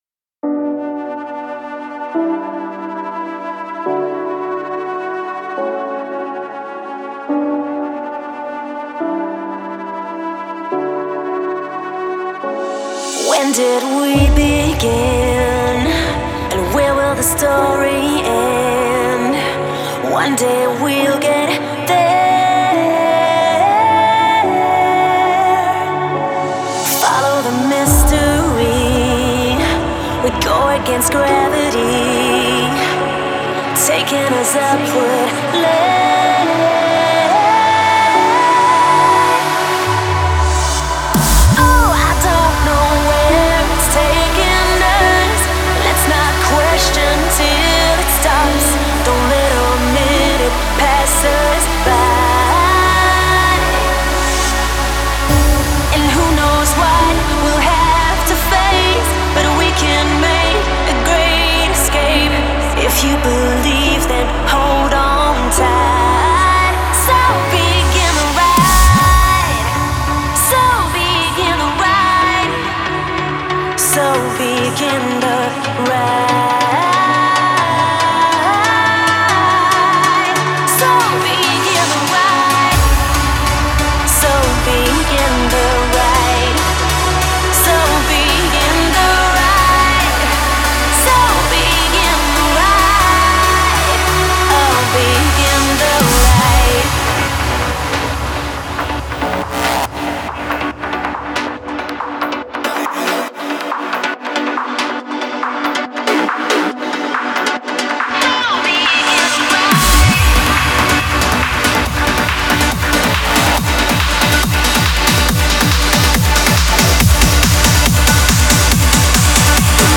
это трек в жанре прогрессивного транс